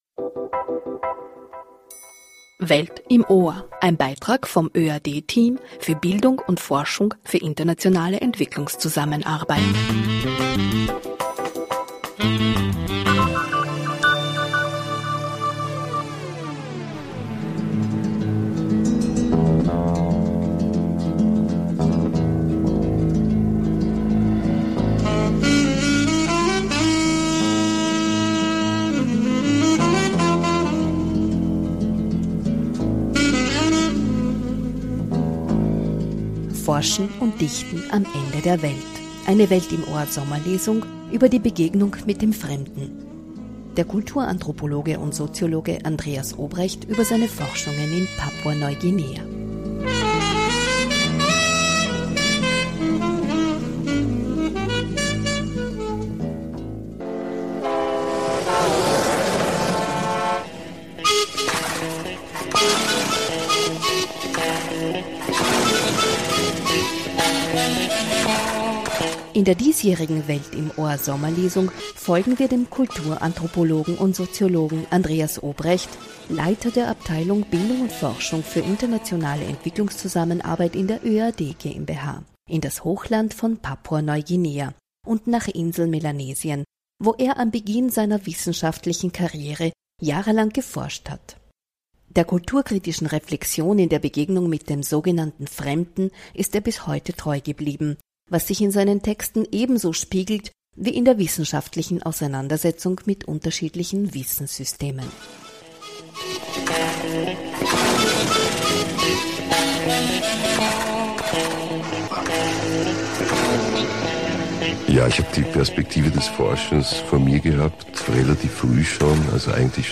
Eine „Welt im Ohr“-Sommerlesung über die Begegnung mit dem Fremden (Whg.)